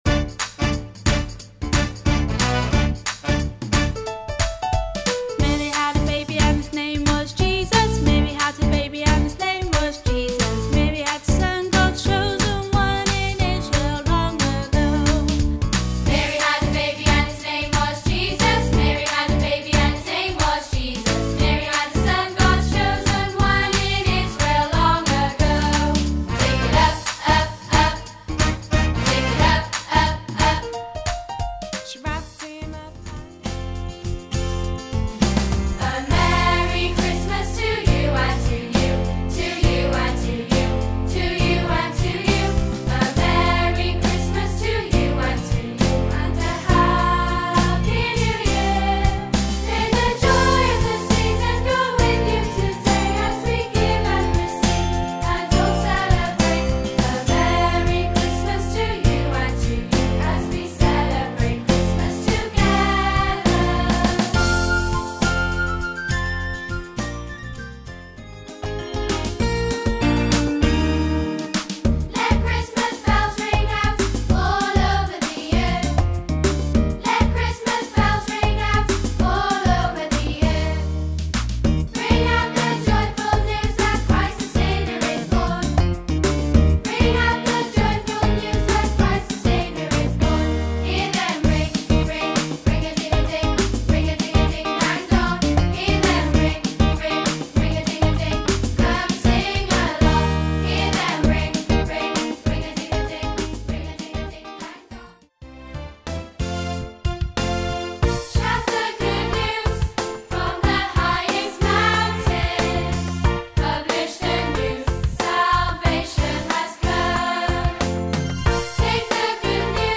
festive songs for Children